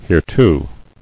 [here·to]